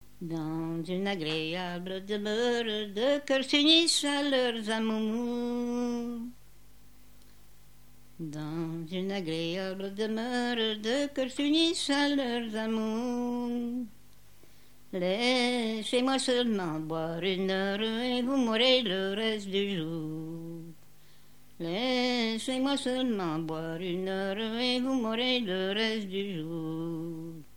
Genre strophique
Chansons traditionnelles et populaires
Pièce musicale inédite